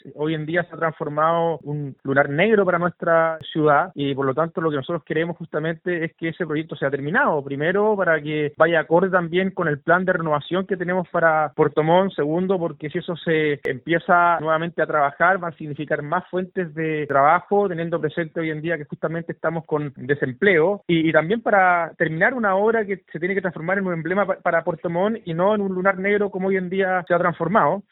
El alcalde Rodrigo Wainraihgt afirmó que esta obra se ha transformado “en un lunar negro para la ciudad”.